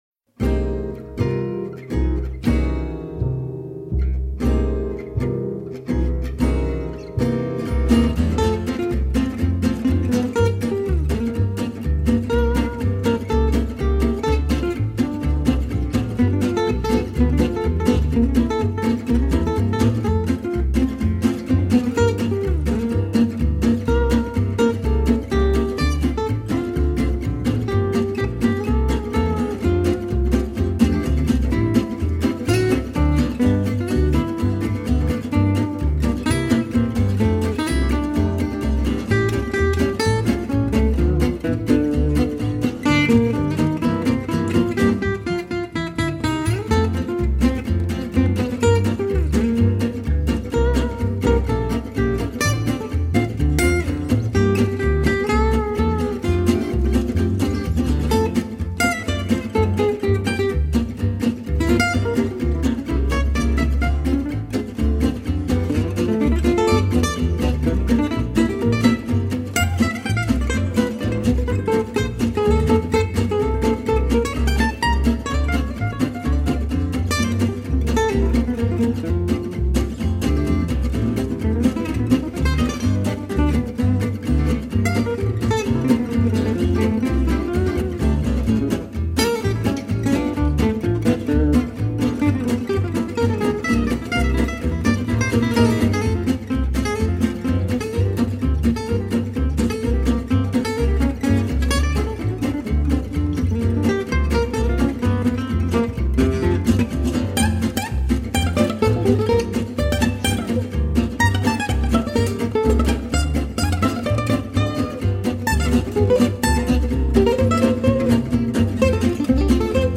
Gypsy Jazz